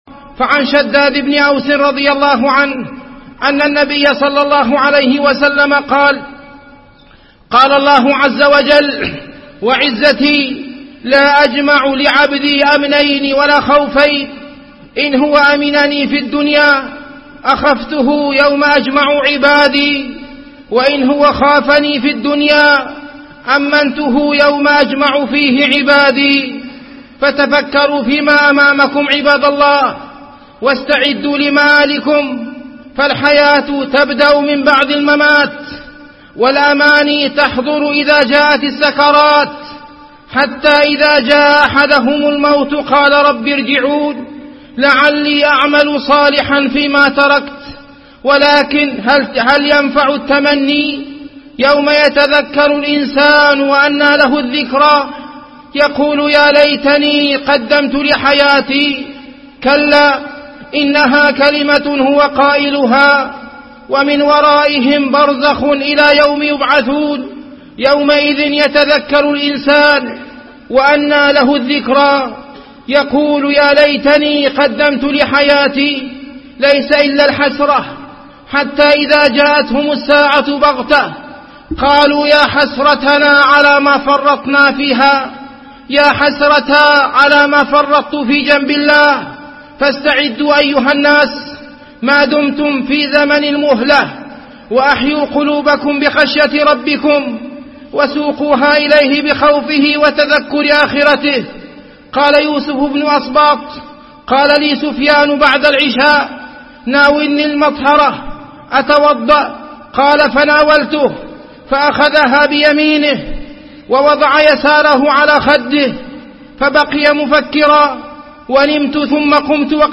موعظة بليغة في خشية الله تعالى